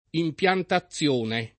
impiantazione [ i mp L anta ZZL1 ne ]